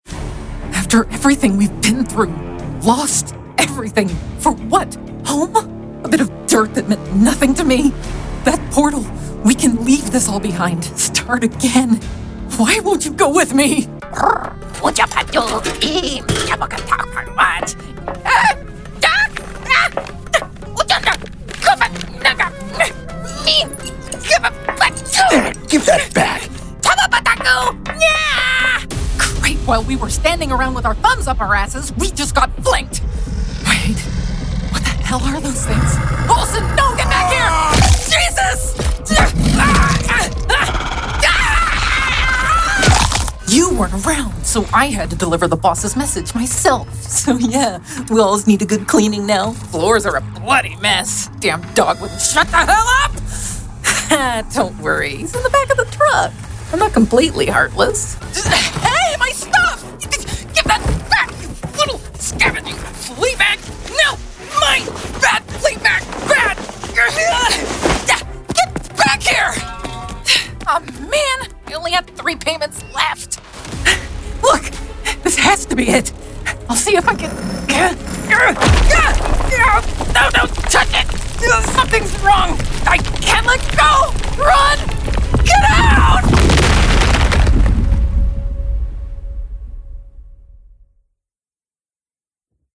Confident, approachable, sincerity that builds connection.
Video Game, acting, action, adventure, epic, alien
General American, American West Coast
Middle Aged